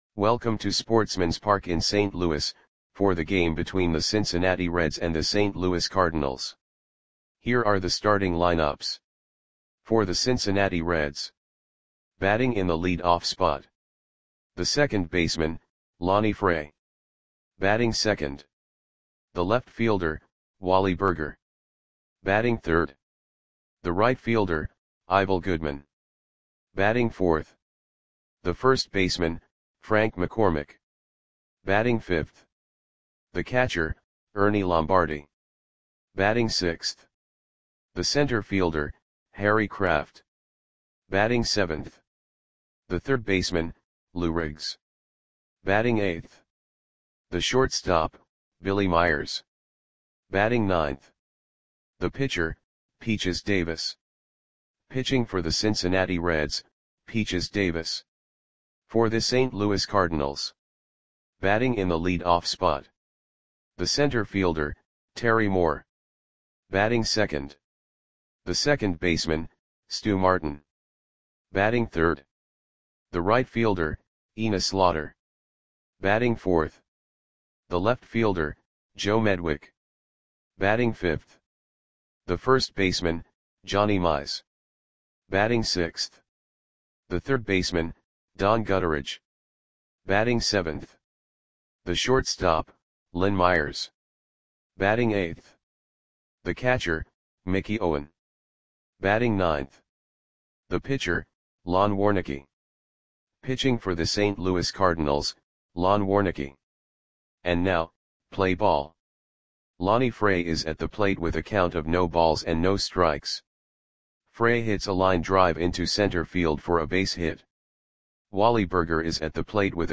Audio Play-by-Play for St. Louis Cardinals on August 20, 1938
Click the button below to listen to the audio play-by-play.